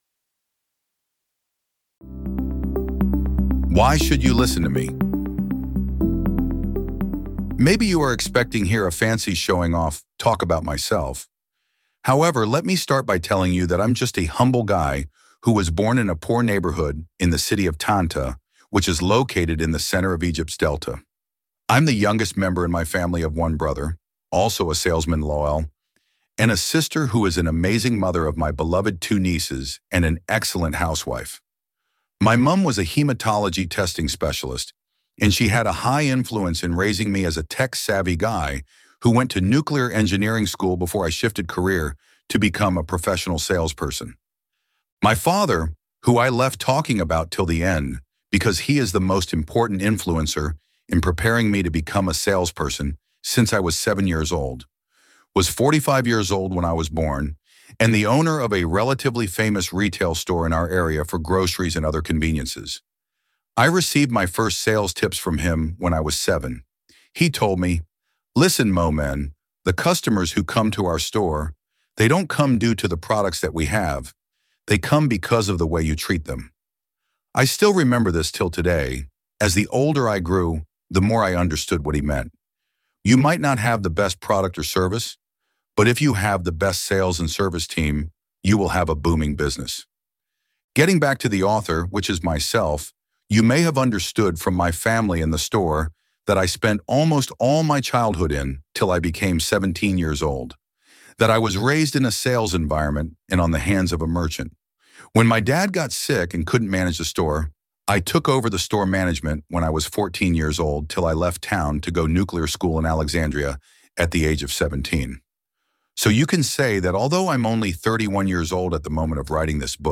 كتاب The Salespedia للكاتب مؤمن أمين مسموع من اي بوك كاست